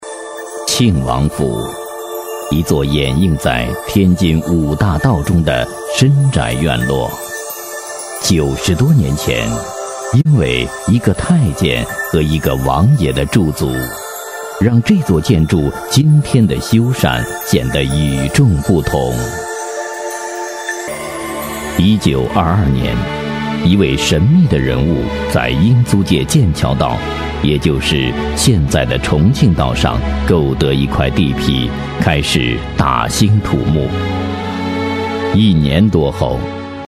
纪录片男17号（庆王府解说
娓娓道来 文化历史
声线偏历史感，非常适合纪录片解说题材。专题、宣传片等题材都可以录。作品：庆王府解说。